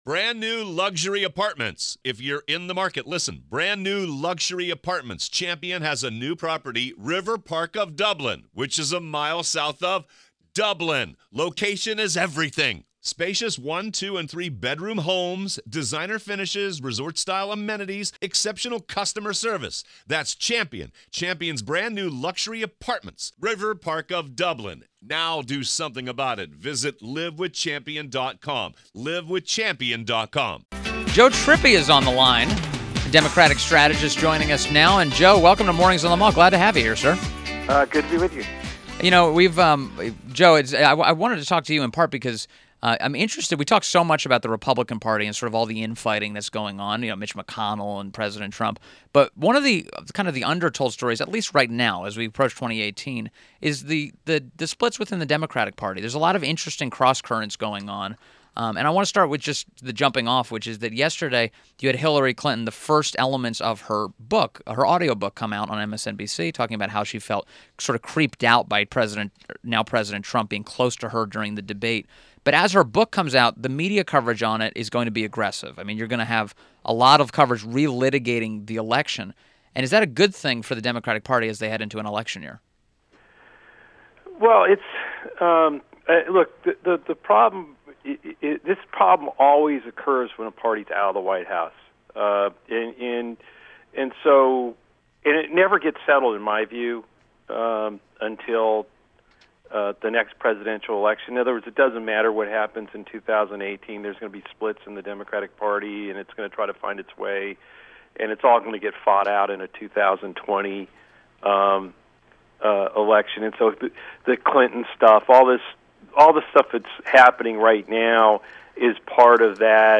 WMAL Interview - JOE TRIPPI 08.24.17
INTERVIEW – JOE TRIPPI – Democratic Strategist and Fox News Contributor